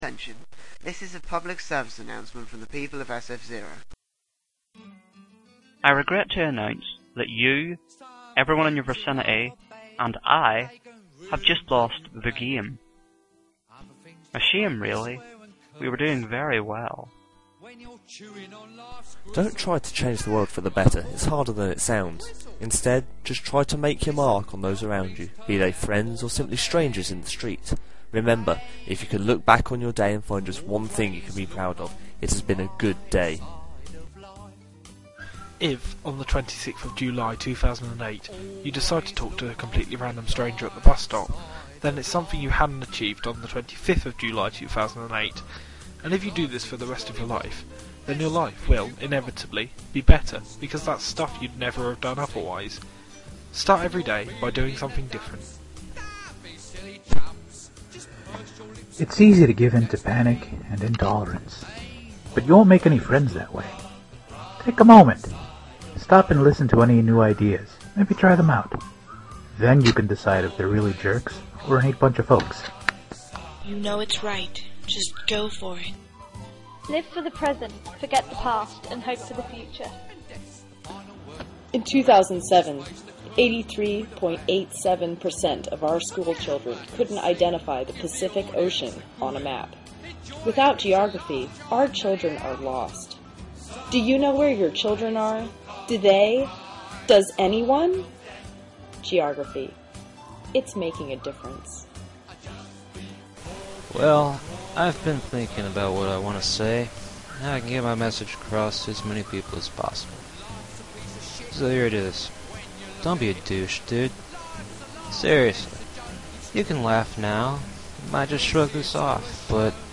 INSTRUCTIONS: Create a public service announcement.